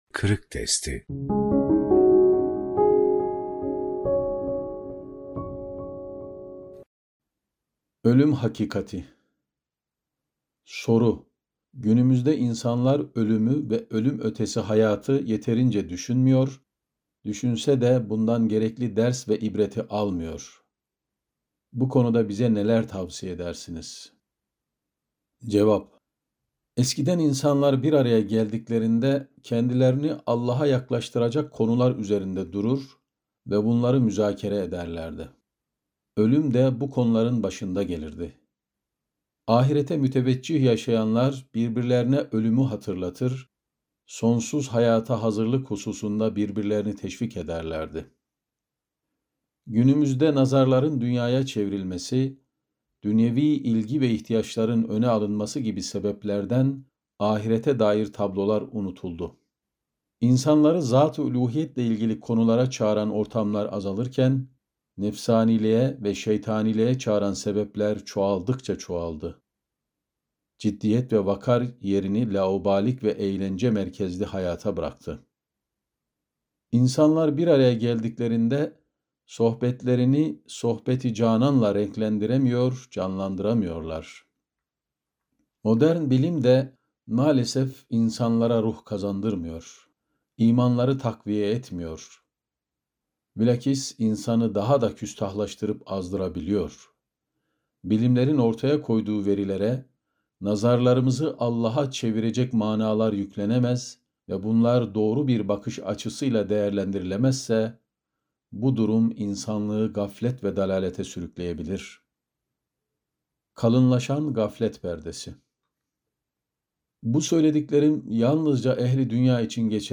Ölüm Hakikati - Fethullah Gülen Hocaefendi'nin Sohbetleri